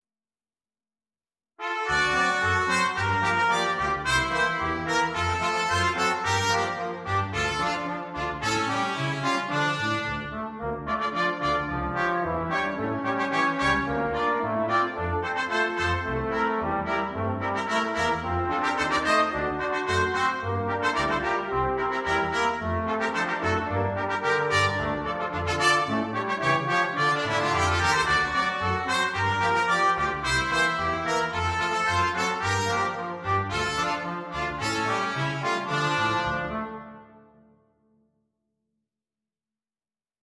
Ноты для брасс-квинтета